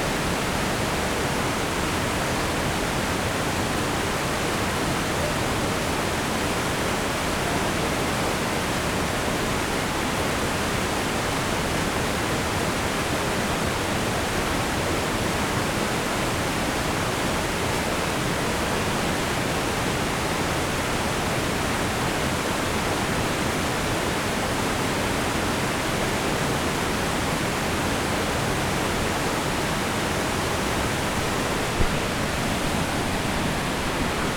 DenverRiver2.wav